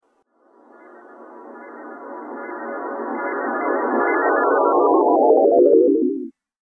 Futuristic-sci-fi-transition-sound-effect.mp3